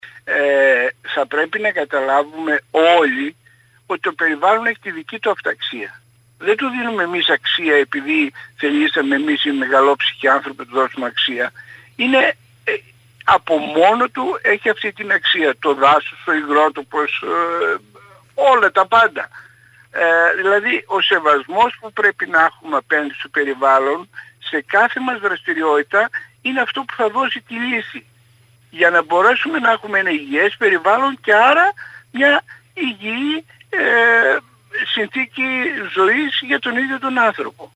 μιλώντας σήμερα Τετάρτη στην ΕΡΤ Κομοτηνής και στην εκπομπή «Καθημερινές Ιστορίες» (2-2-2022) με αφορμή την Παγκόσμια Ημέρα Υγροτόπων.